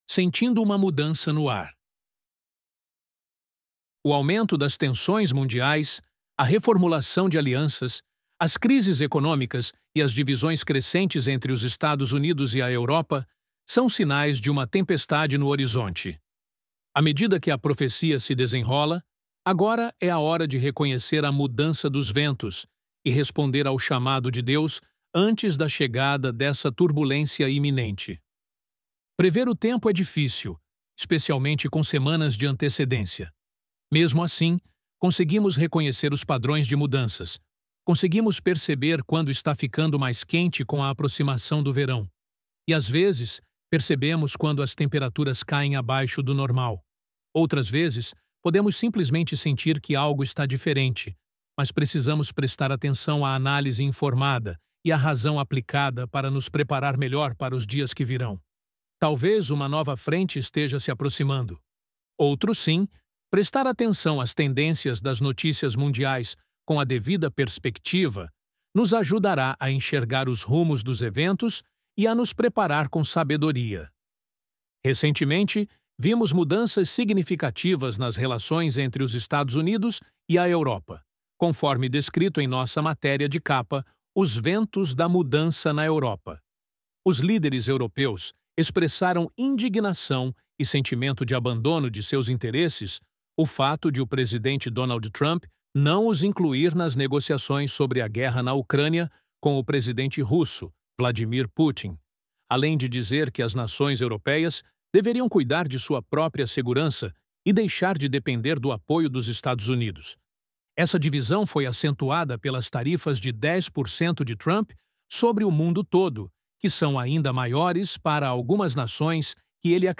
Loading the Elevenlabs Text to Speech AudioNative Player...
ElevenLabs_Sentindo_Uma_Mudança_No_Ar.mp3